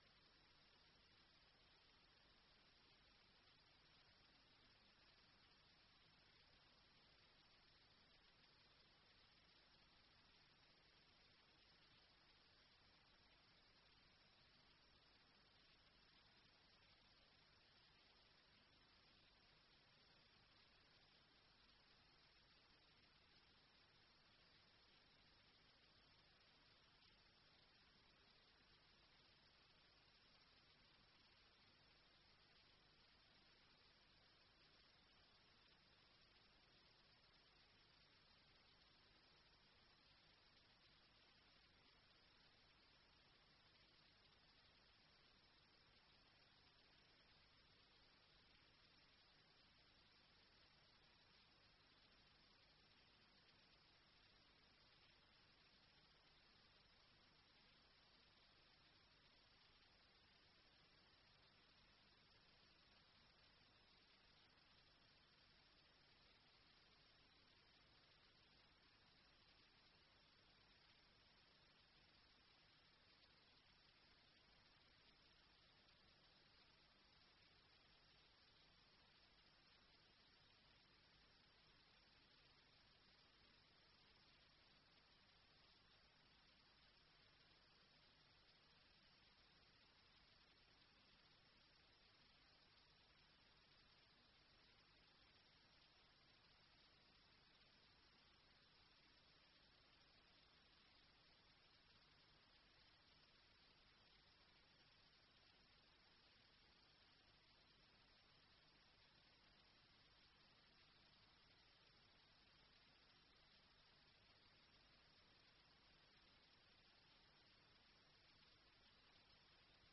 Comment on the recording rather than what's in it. Committee Scrutiny Climate Change and Environment Committee Meeting Date 24-06-24 Start Time 6.30pm End Time 8pm Meeting Venue Coltman VC Room, Town Hall, Burton upon Trent Please be aware that not all Council meetings are live streamed.